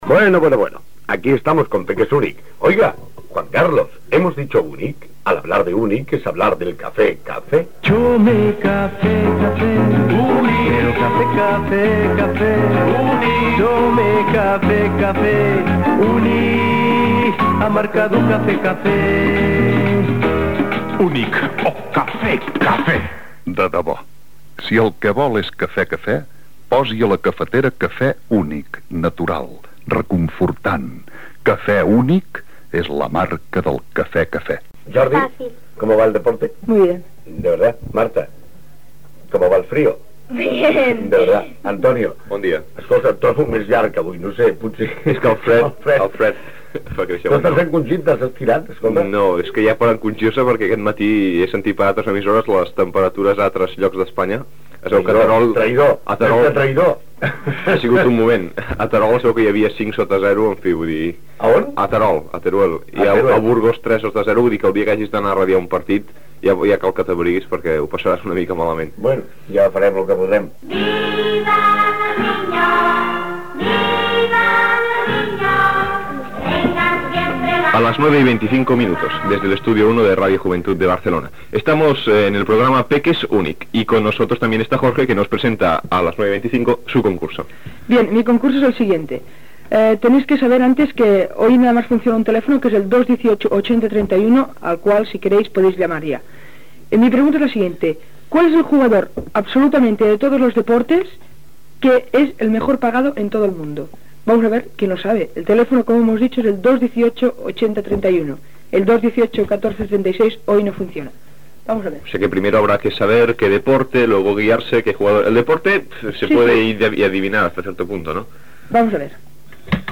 Publicitat, hora, presentació de concurs i pregunta: quin és el jugador que cobra més diners de tot el món? Participació telefònica dels oients.
Infantil-juvenil